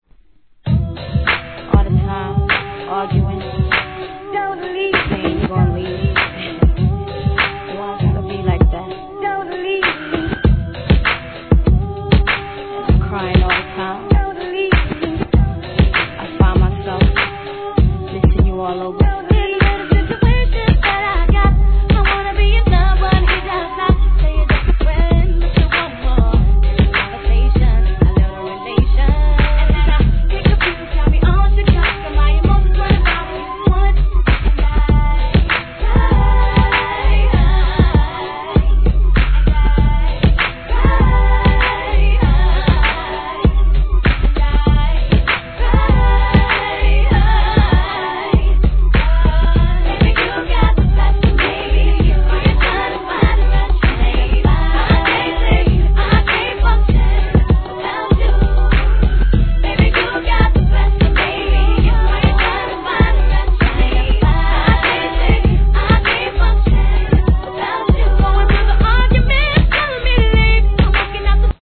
HIP HOP/R&B
フロア栄えするトラックのA面、全編にストリングスをフュ−チャ−したB面共に仕えます!!